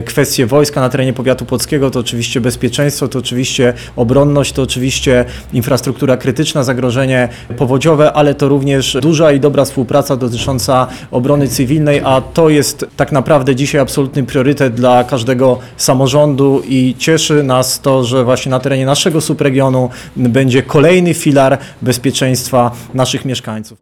W poniedziałek, 15 grudnia, w Starostwie Powiatowym w Płocku odbyła się konferencja prasowa poświęcona podpisaniu aktu notarialnego dotyczącego obecności jednostki Wojsk Obrony Terytorialnej na terenie powiatu płockiego.
Jak podkreślał Starosta Płocki Sylwester Ziemkiewicz, kwestia bezpieczeństwa to priorytet dla każdego samorządu.